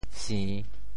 潮州 buê6 sin3 ham1 潮阳 buê6 sin3 ham1 潮州 0 1 2 潮阳 0 1 2